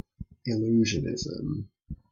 Uttal
Synonymer magic Uttal : IPA : /ɪˈl(j)uːʒənɪz(ə)m/ Ordet hittades på dessa språk: engelska Ingen översättning hittades i den valda målspråket.